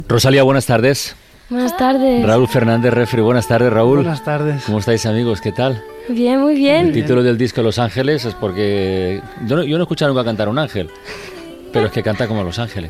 Inici de l'entrevista.